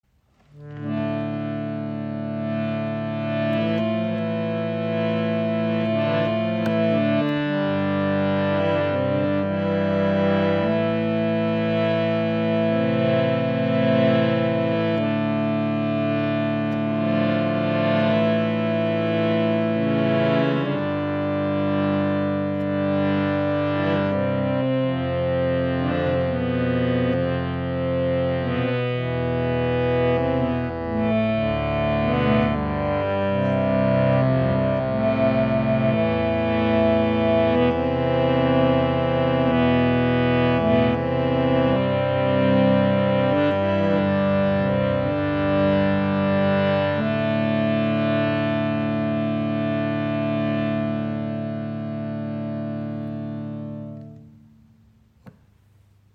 Harmonium | Bhava Lite Travel | Limited Edition Dark Cedar
Das Bhava Travel Lite in der Limited Edition kombiniert schicke Designelemente mit einer Zedernholzkonstruktion und hat einen warmen Klang und ein unverwechselbares Aussehen.
Das Bhava Lite ist ein minimalistisches Reiseharmonium mit 32 Tasten.
Bordune: keine
Stimmung: Concert Pitch / 440 Hz